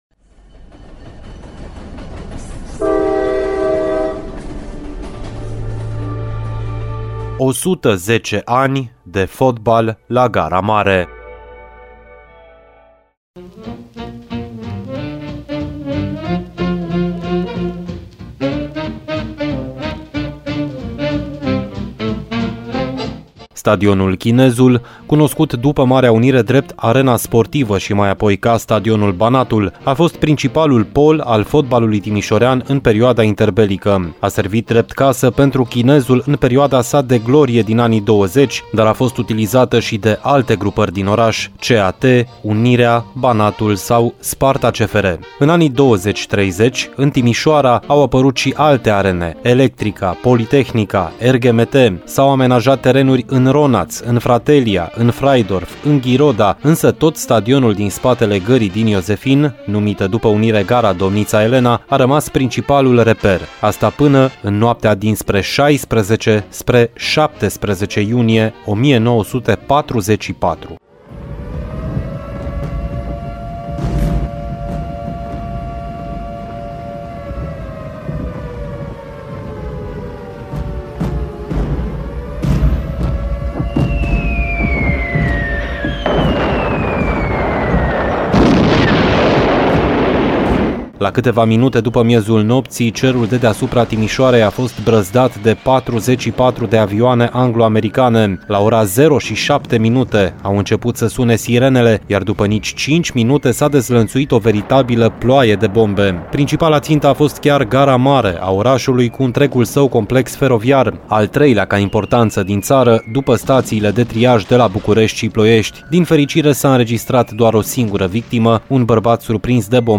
a fost difuzat în ediția de astăzi a emisiunii Arena Radio.